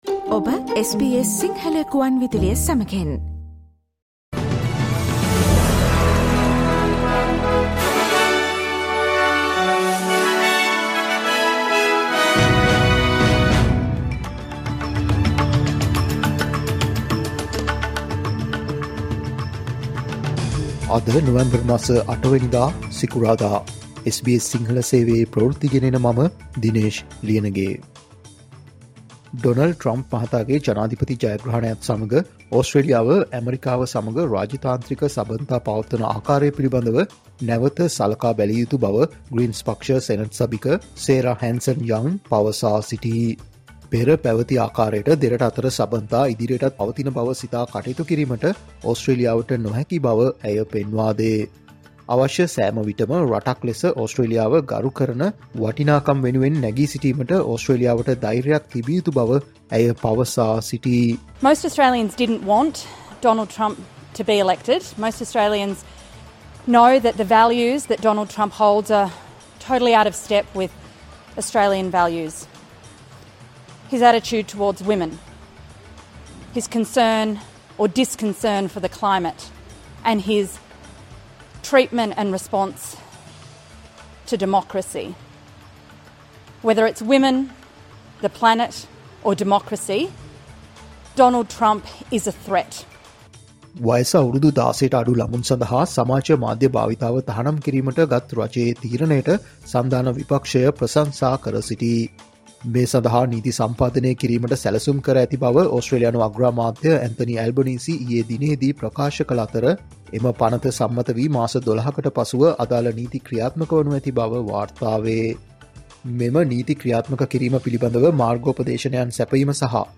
Australia's news in Sinhala.